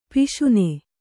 ♪ piśune